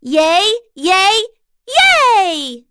Dosarta-Vox_Victory.wav